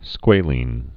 (skwālēn)